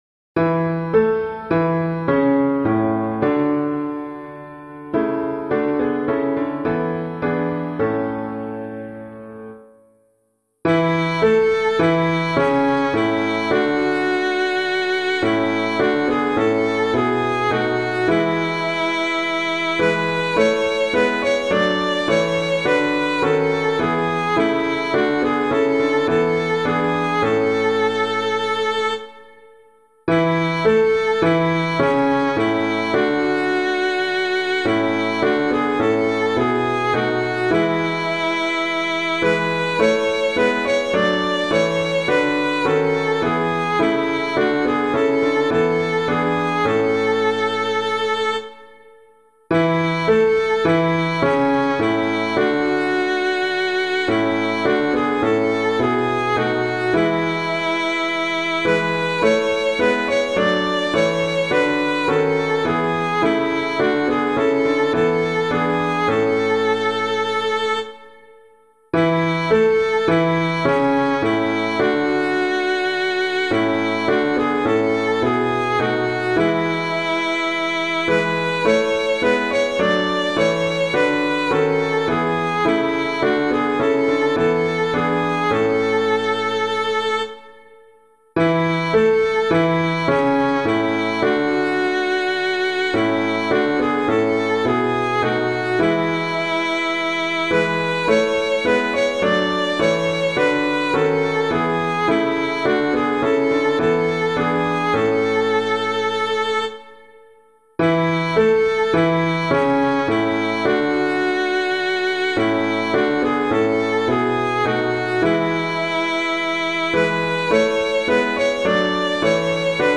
piano
How Good It Is for Us [Joncas - FESTAL SONG] - piano.mp3